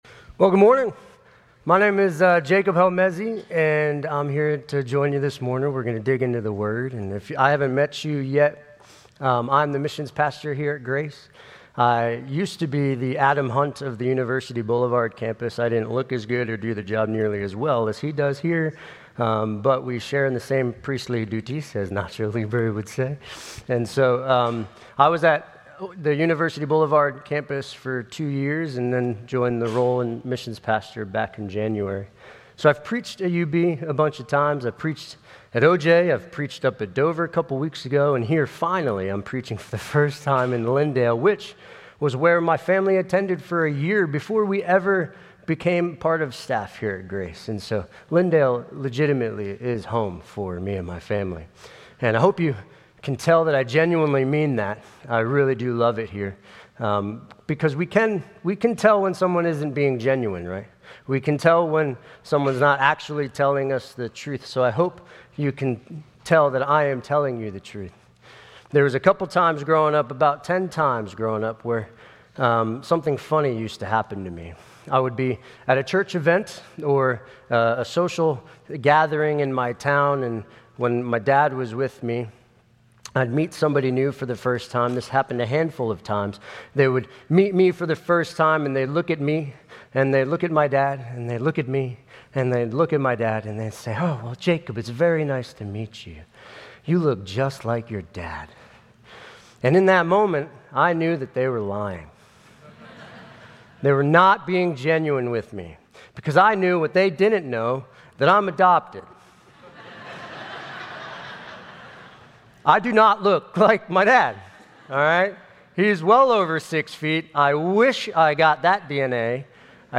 Grace Community Church Lindale Campus Sermons 8_17 Lindale Campus Aug 17 2025 | 00:25:43 1x 00:00 / 00:25:43 Subscribe Share RSS Feed Share Link Embed